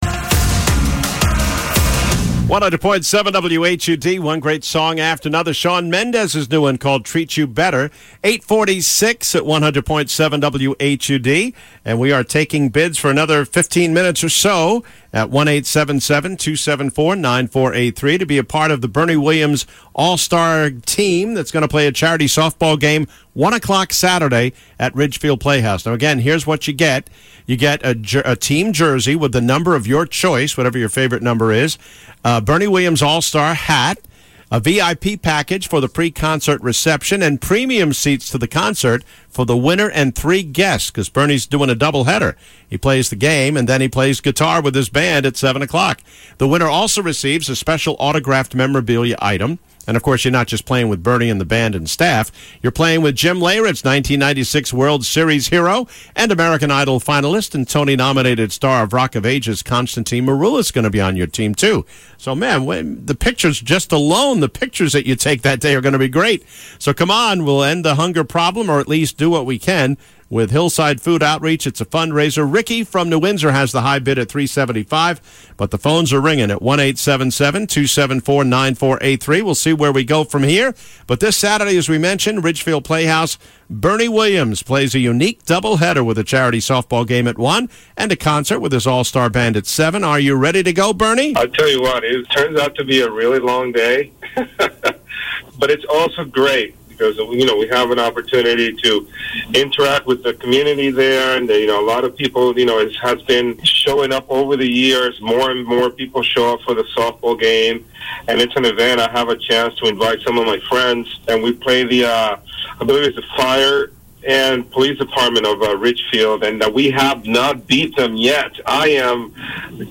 Interview with former Yankee Bernie Williams